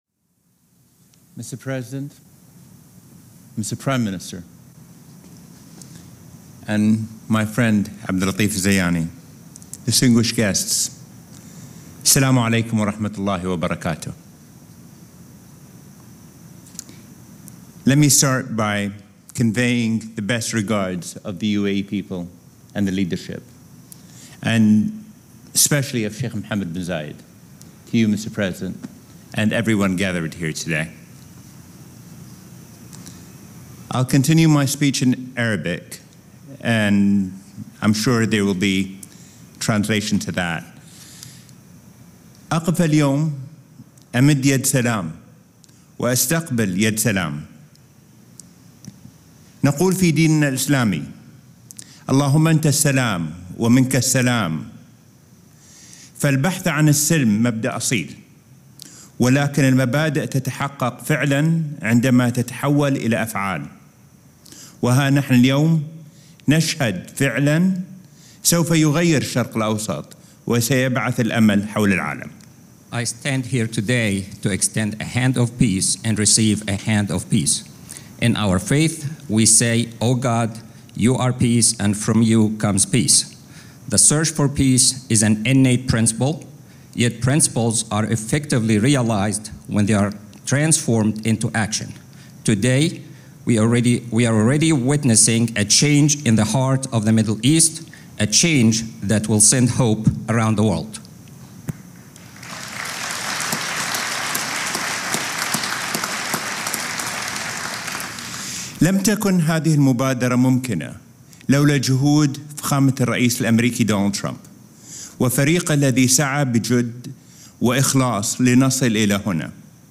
Abdullah bin Zayed Al Nahyan - Abraham Accords Signing Speech (transcript-audio-video)